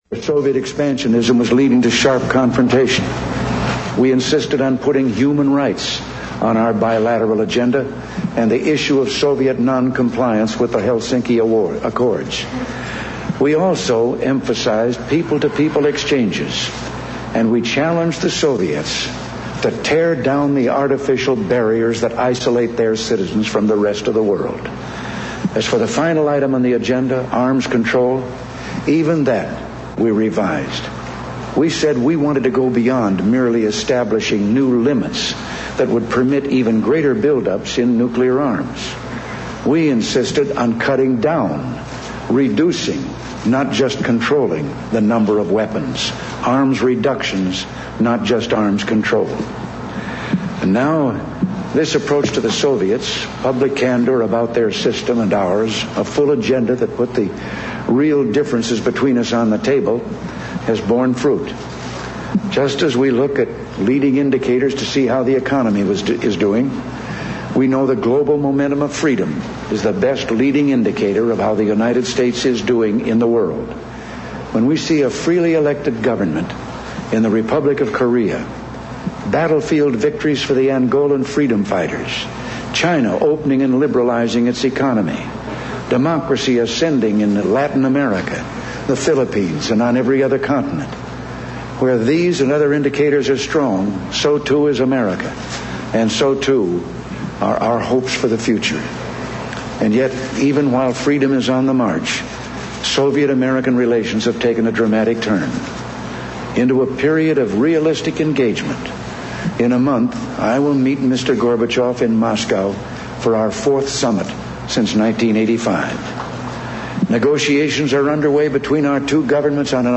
Ronald Reagan addresses the World Affairs Council in Springfield, Massachusetts
Broadcast on CNN, April 21, 1988.